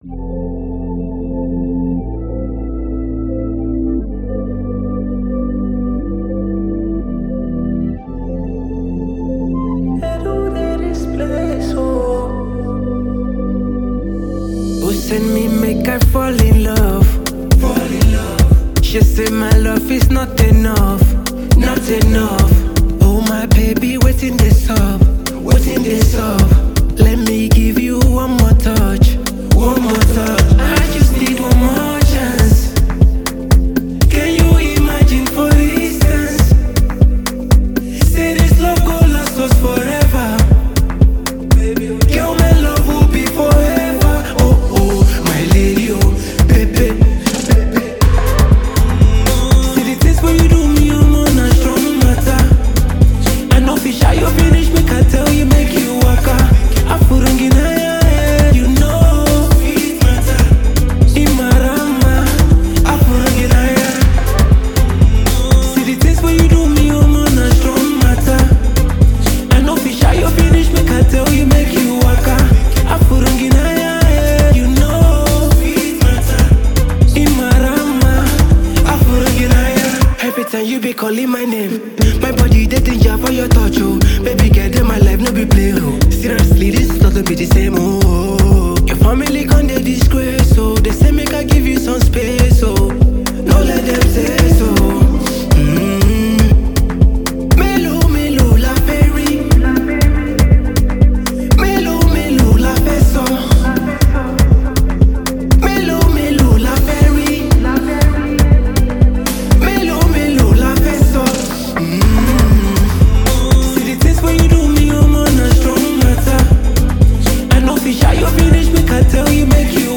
Afrobeats
Blending soulful melodies with relatable lyrics
a deeply moving sound